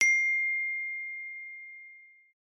Ding (1)